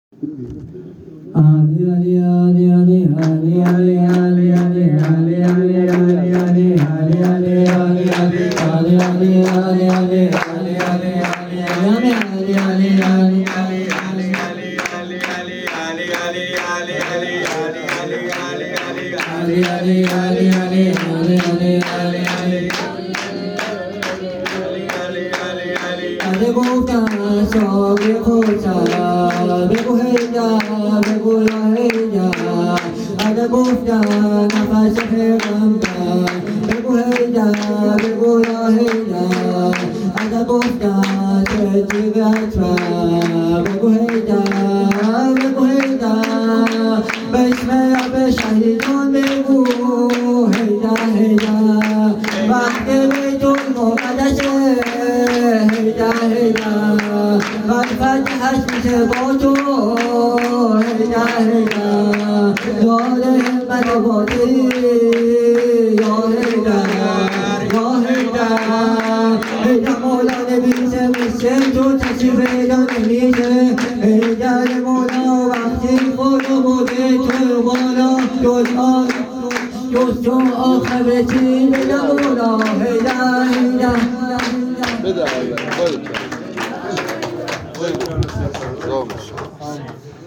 هیئت روضه الزهرا تهران